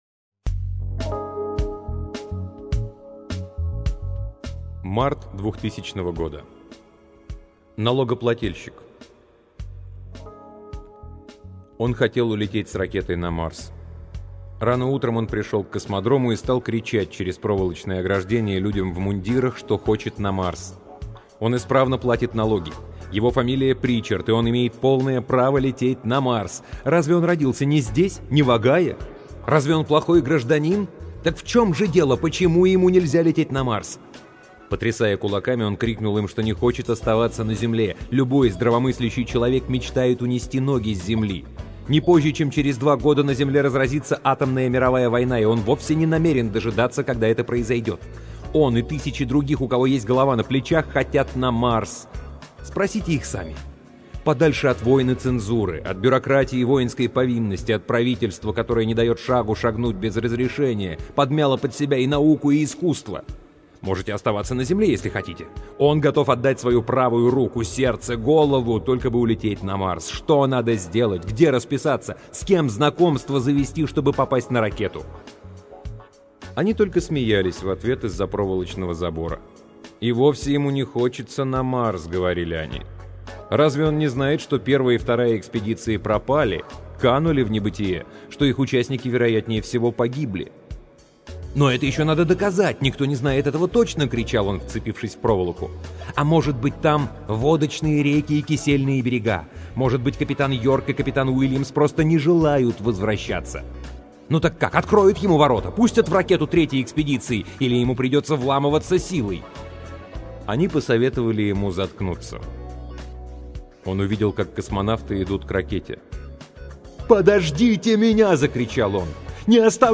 Аудиокнига Рэй Брэдбери — Налогоплательщик